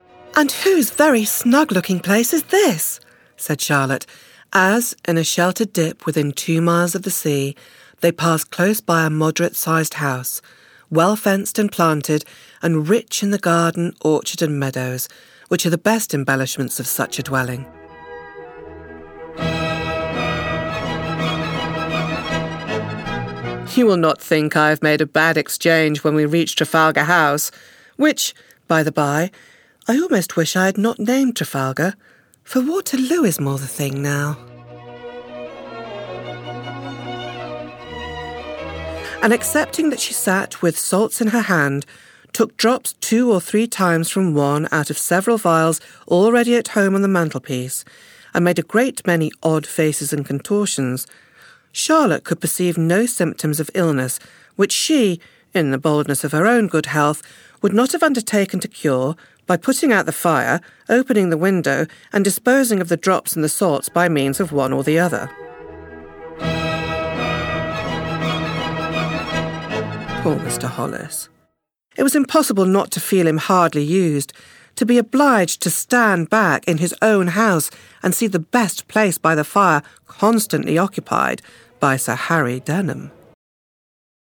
Audiobook Showreel
Soft, sensible and sorted but with a savvy, knowing sense of humour.
Female
British RP
Neutral British
Smooth
Reassuring
Friendly